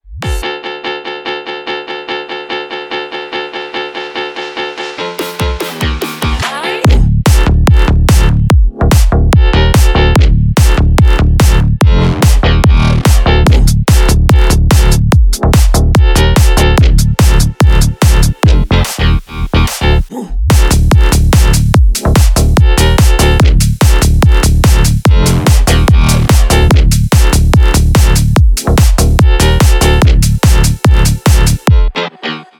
Громкие рингтоны